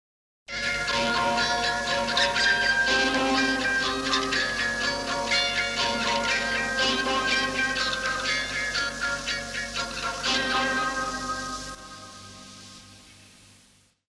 Ping_Pong_Effekt.mp3